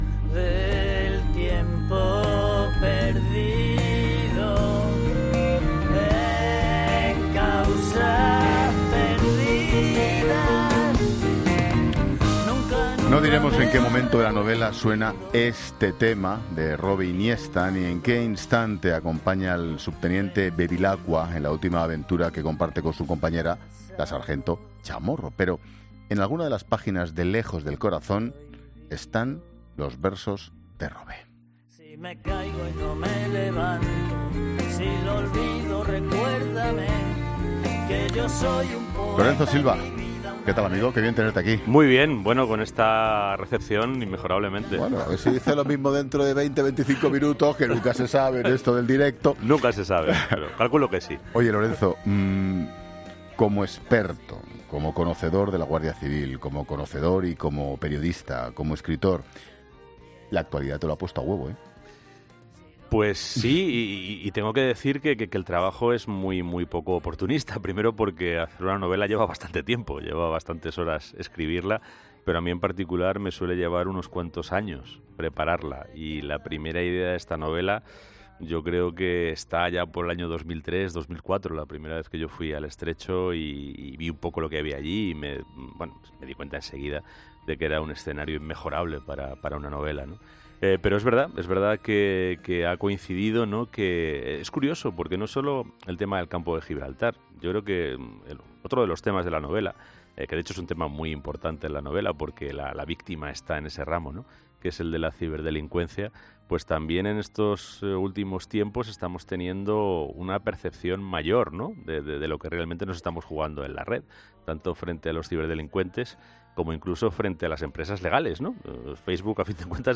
Escucha la entrevista a Lorenzo Silva en 'La Tarde', jueves 24 de mayo de 2018